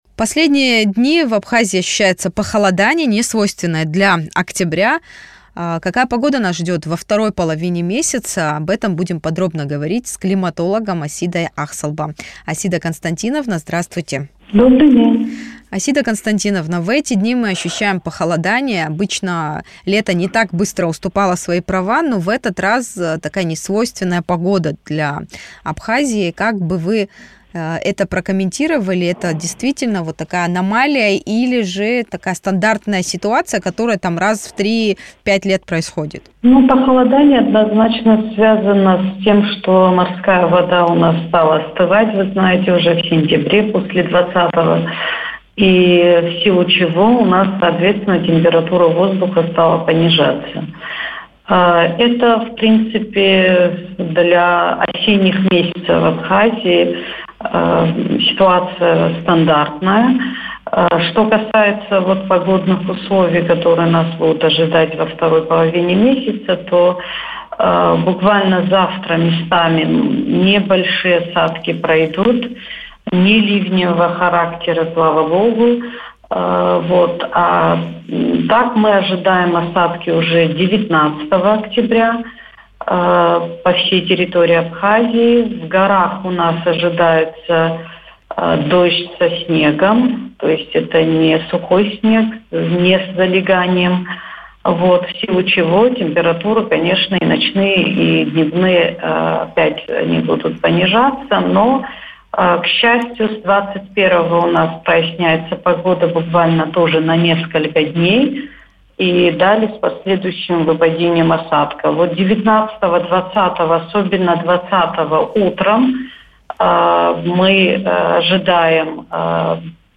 Актуальный комментарий
климатолог